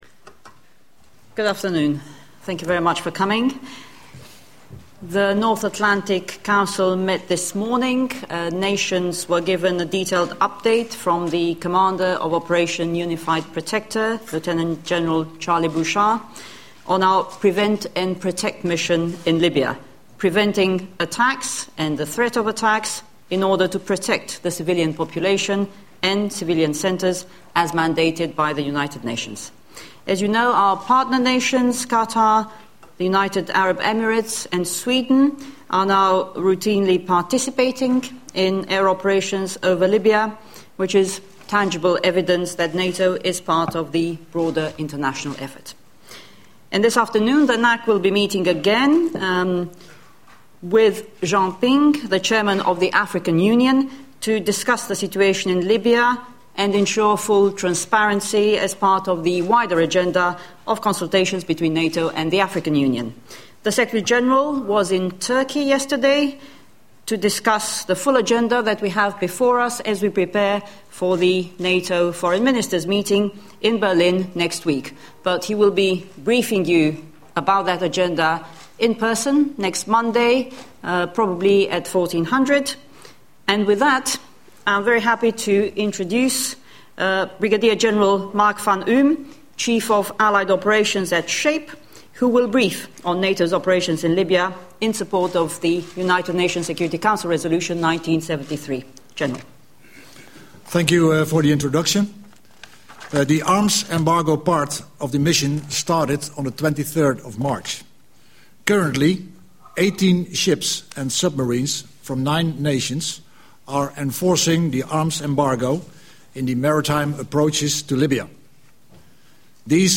Point de presse conjoint sur la Libye par la porte parole de l'OTAN et par le chef des opérations interalliées, le général de brigade Mark van Uhm
Le 5 avril, la porte parole de l'OTAN, Mme Oana Lungescu, et le général de brigade Mark van Uhm, chef des opérations interalliées au Commandement allié Opérations (SHAPE), ont fait un exposé à la presse sur l'opération menée par l'OTAN en Libye à l'appui de la résolution 1973 du Conseil de sécurité de l'ONU.